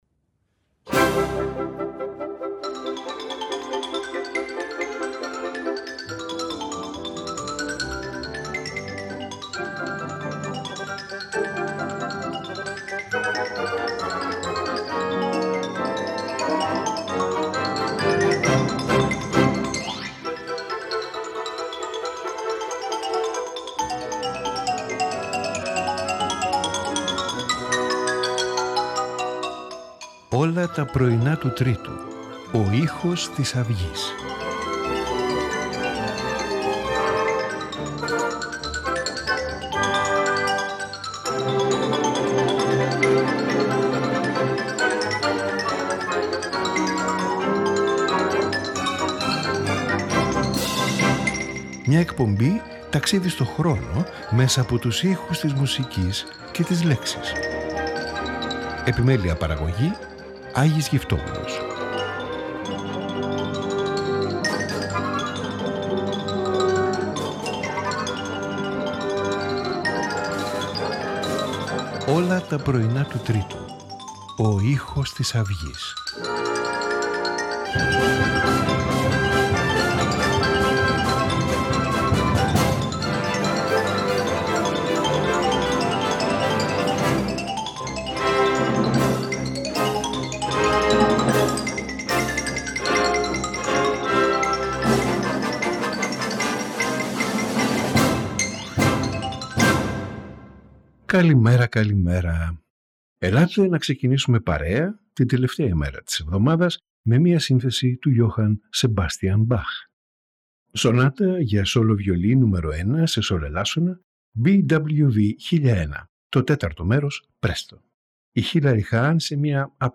J.S. Bach – Sonata for Solo Violin No. 1 in G minor, BWV 1001: IV. PrestoJean Sibelius – Symphony No. 3 in C, Op.52Mel Bonis – Suite for Trio, Op.54Giovanni Battista Sammartini – Sinfonia in D, JC 11